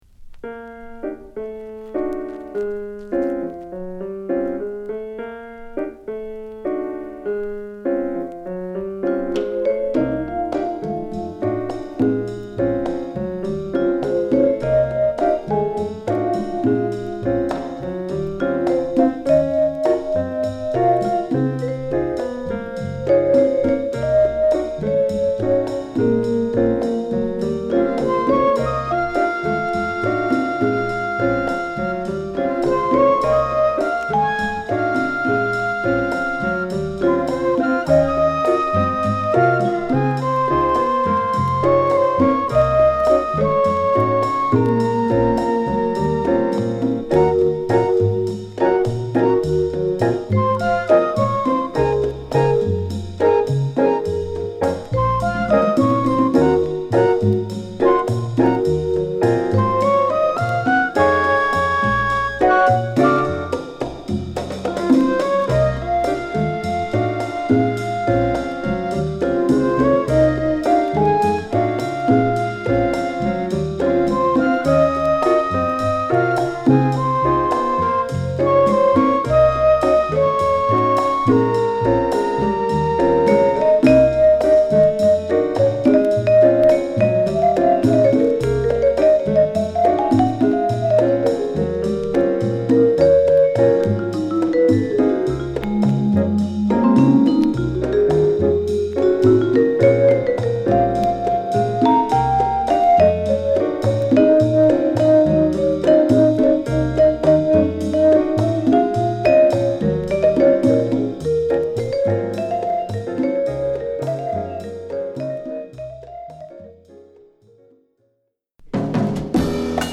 心は熱くサウンドは涼しく、極上ラテンジャズを満載した本作。